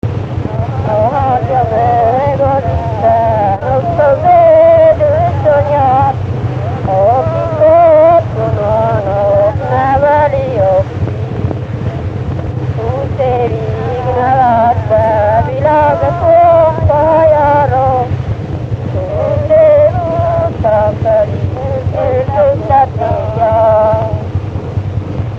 Erdély - Udvarhely vm. - Szentegyházasfalu
Stílus: 8. Újszerű kisambitusú dallamok
Kadencia: 1 (b3) 7 1